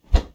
Swing On Air
Close Combat Swing Sound 80.wav